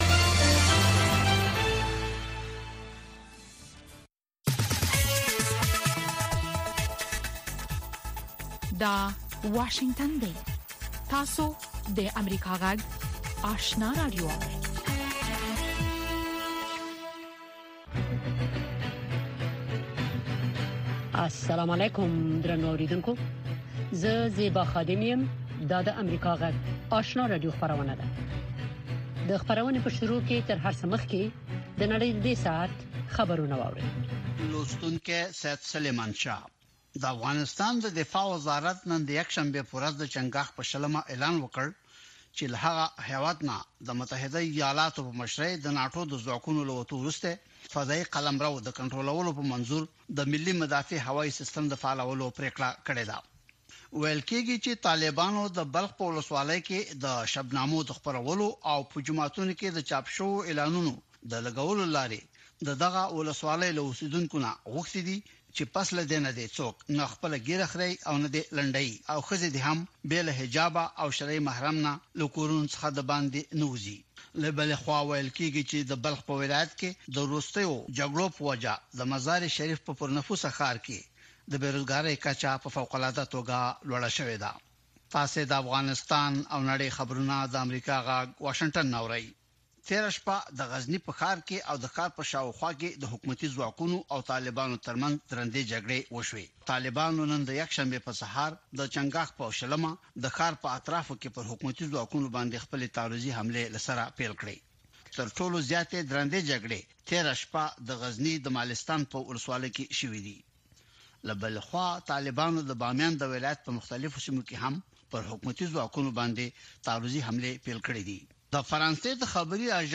دوهمه ماښامنۍ خبري خپرونه